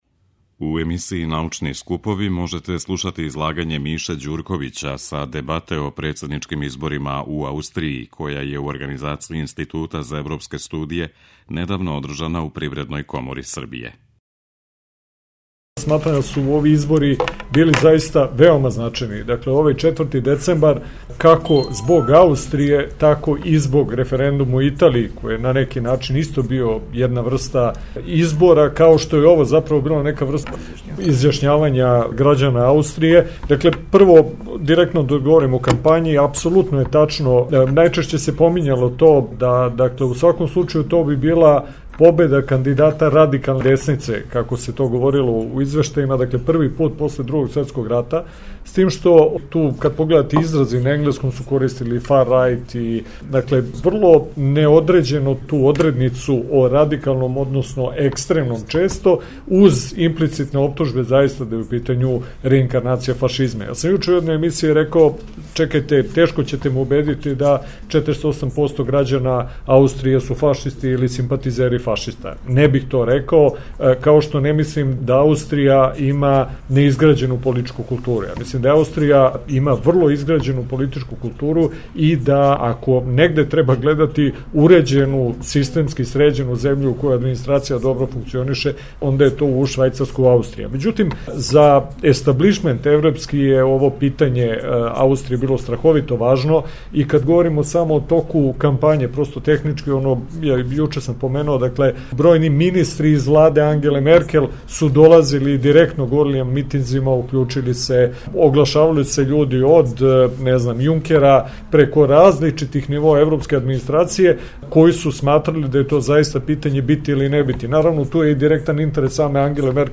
преузми : 6.98 MB Трибине и Научни скупови Autor: Редакција Преносимо излагања са научних конференција и трибина.